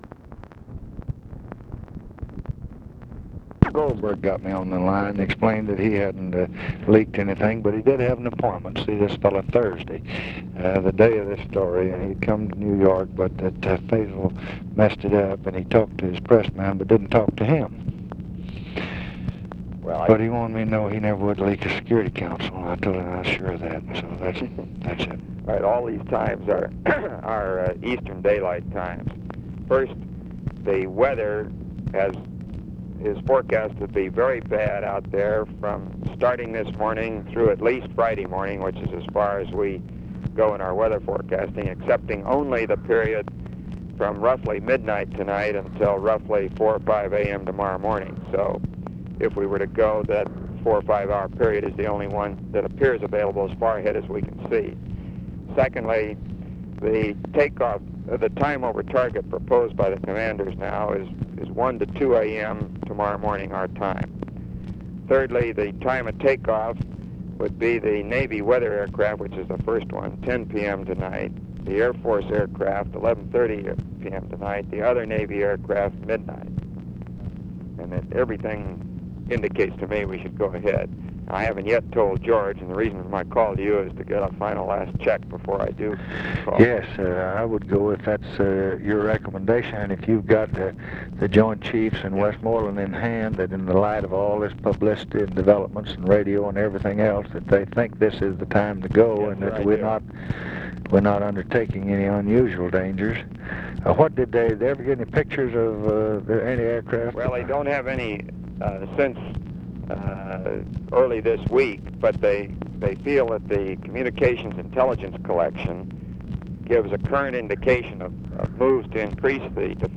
Conversation with ROBERT MCNAMARA, June 28, 1966
Secret White House Tapes